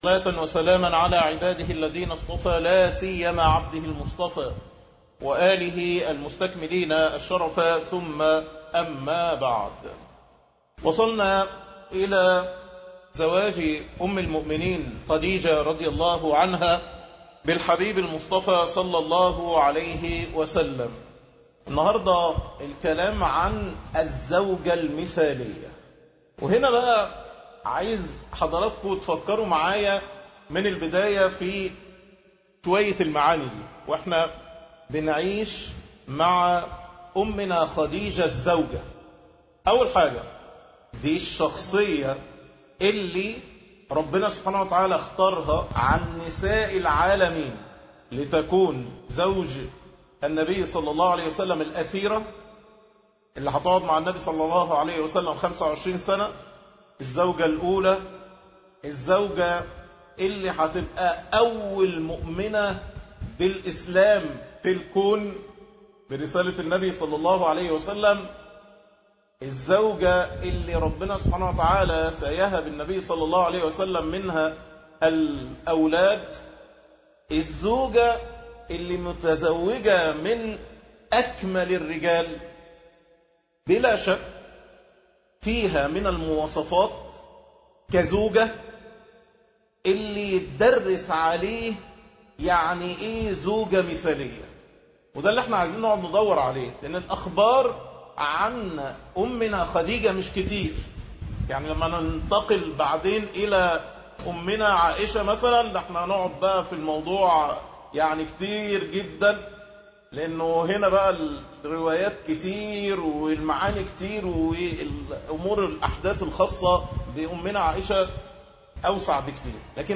الدرس الخامس الزوجة المثالية ( هؤلاء أمهاتنا )